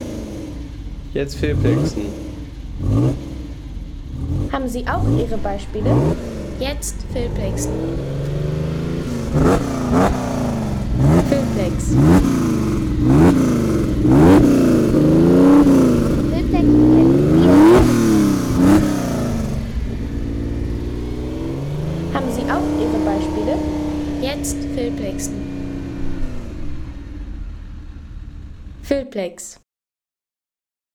Ferrari Dino 246 Sound von 1972 | Feelplex
Klassischer Ferrari-Dino-246-Sound von 1972 mit aufheulendem Motor und markantem Davonfahren.
Der Ferrari Dino 246 von 1972 mit intensivem Aufheulen des Motors und markantem Davonfahren.